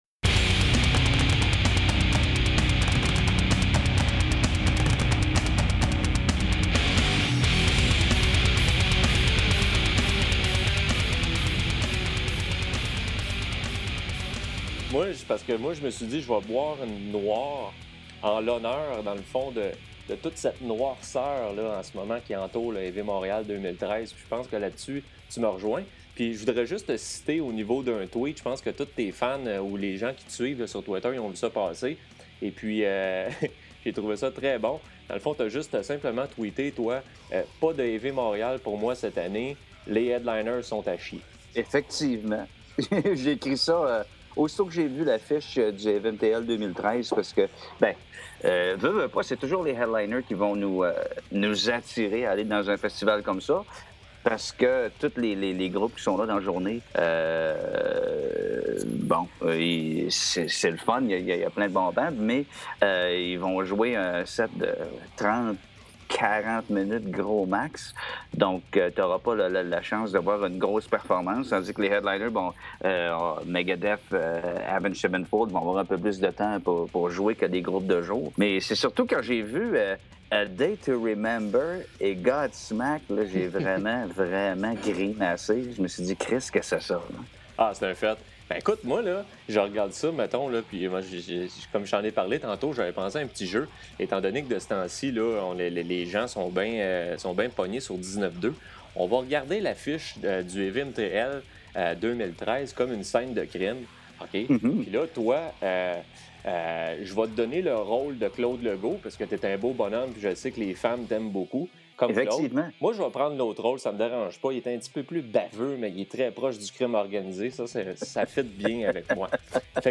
(Audio) Notre analyse du HEAVY MTL 2013